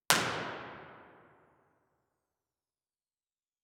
Description: Reverberant HOW. This is a comparison of the house line array with a med Q coaxial source on stage.
IR_TP3_97ft_point.wav